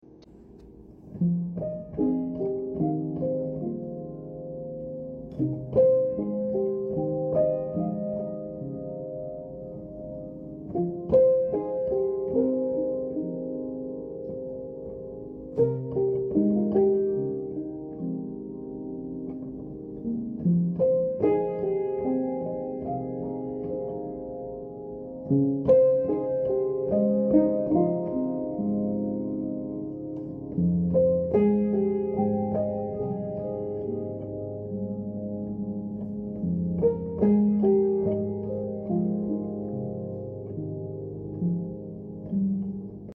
Throwback To Playing The Piano Sound Effects Free Download